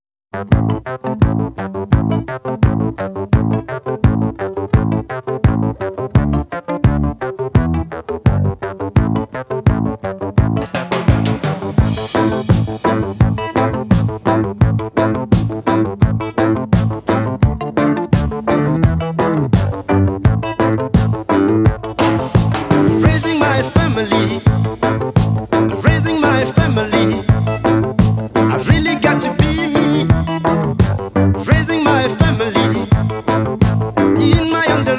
Жанр: Африканская музыка